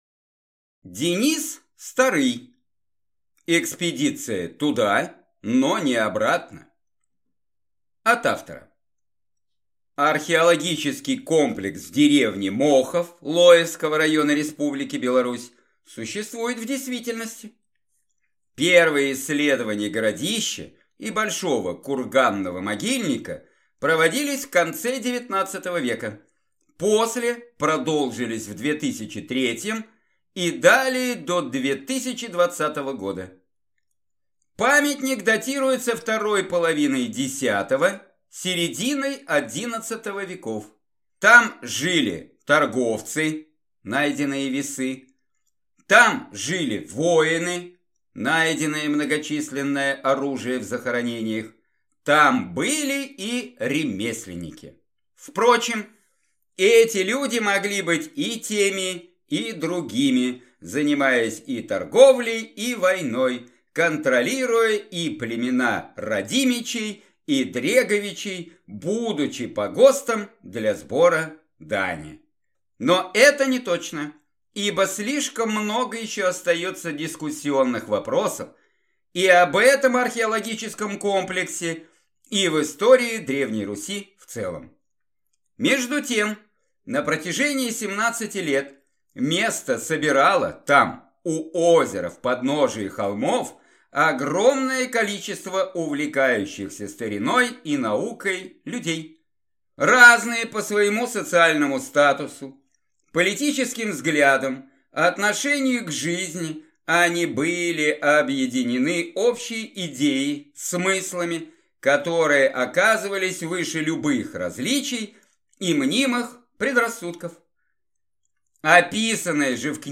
Аудиокнига «Кровь Василиска. Том 4».